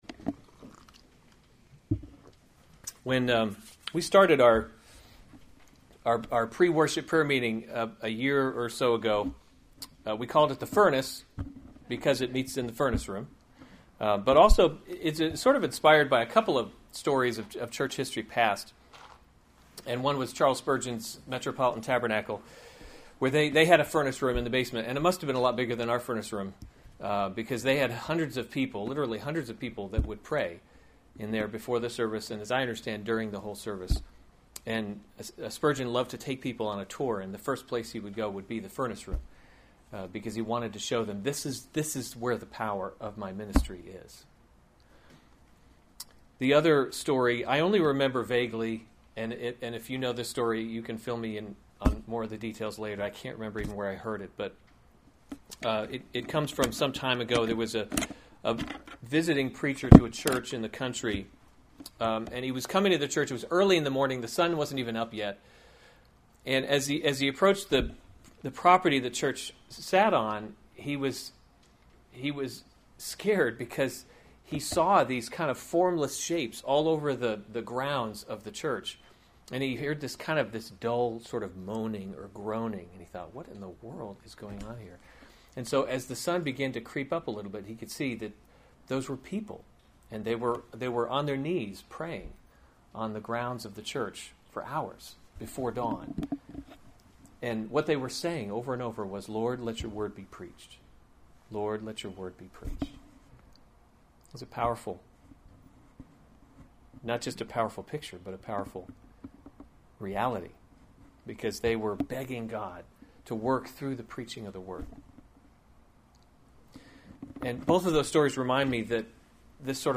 June 11, 2016 2 Thessalonians – The Christian Hope series Weekly Sunday Service Save/Download this sermon 2 Thessalonians 3:1-5 Other sermons from 2 Thessalonians Pray for Us 3:1 Finally, brothers, [1] pray […]